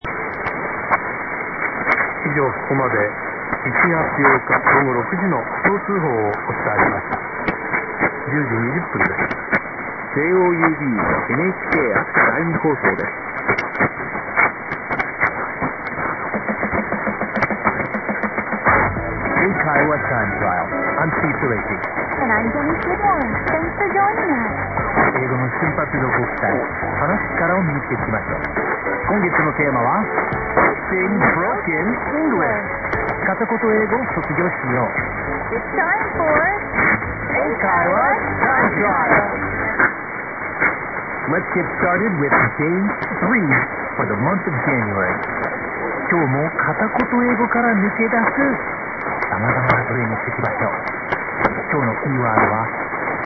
Since there wasn't much DX today, I've posted a recording of yesterday's JOUB log -